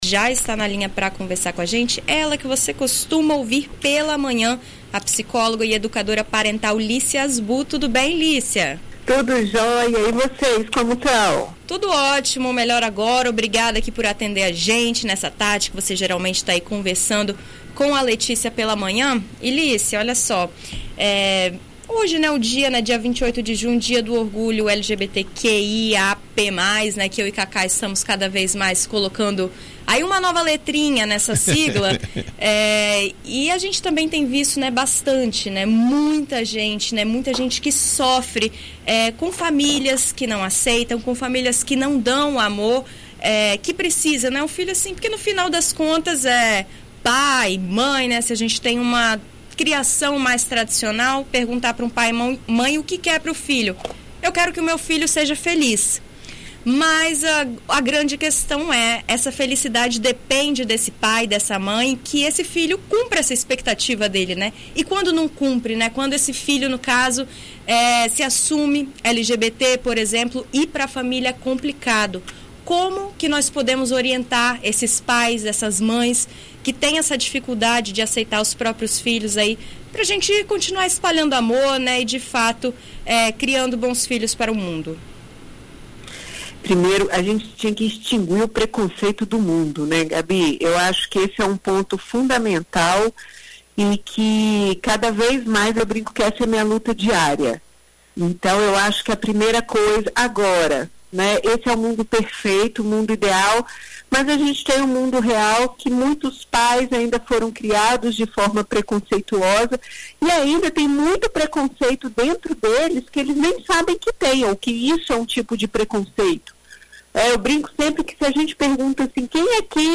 Em entrevista concedida à BandNews FM ES